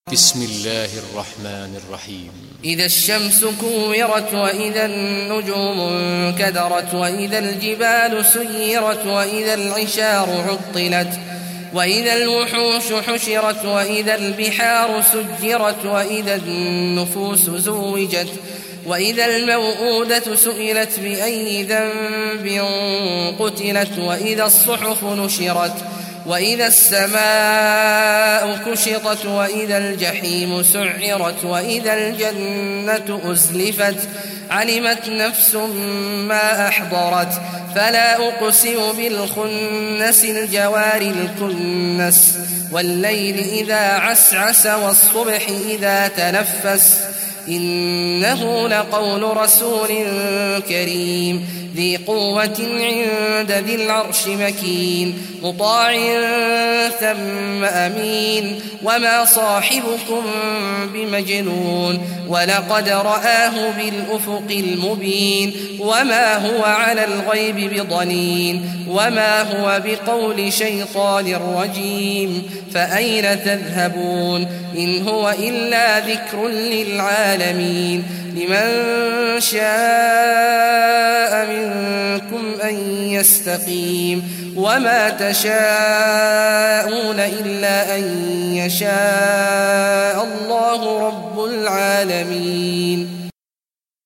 Surah Takwir Recitation by Sheikh Awad Juhany
Surah Takwir, listen or play online mp3 tilawat / recitation in Arabic in the beautiful voice of Sheikh Abdullah Awad al Juhany.